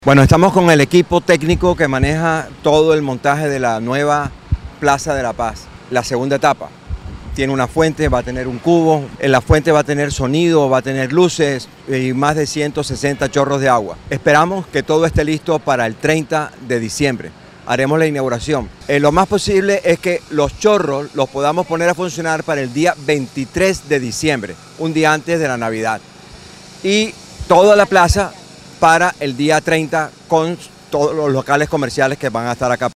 Audio.-Eduardo-Verano-gobernador-del-Atlántico-Anuncio-Plaza-de-la-Paz.mp3